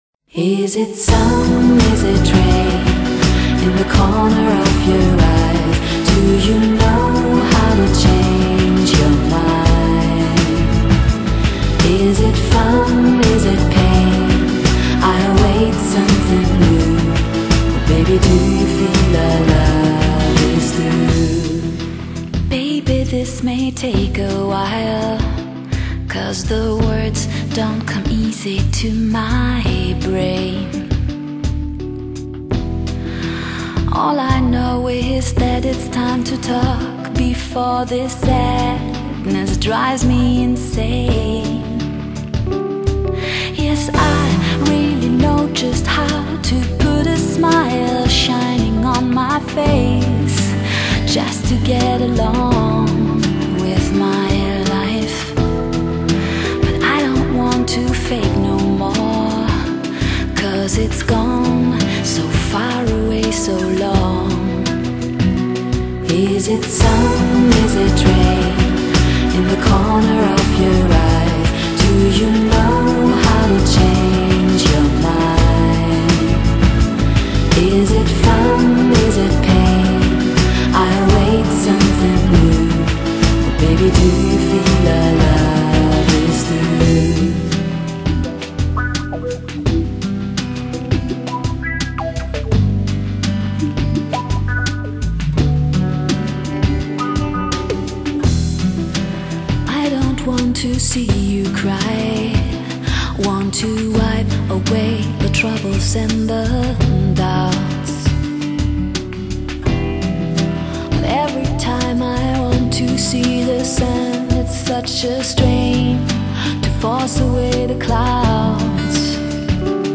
Genre: Chillout, Lounge